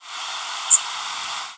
Zeep calls
Magnolia Warbler
The calls are short, typically between 1/20th and 1/10th of a second (50-100 mS) in duration, and high-pitched, typically between 6-10 kHz. The calls have an audible modulation that gives them a ringing, buzzy, or sometimes trilled quality.
Examples 3 & 4 of Magnolia Warbler illustrate such rising flight calls.